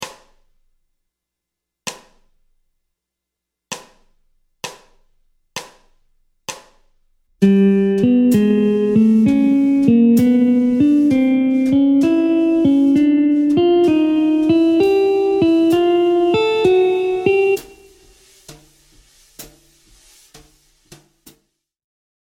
Mode Lydien b7 ( IV mineur mélodique)
Montée de gamme
Gamme-bop-asc-Pos-42-C-Lydien-b7.mp3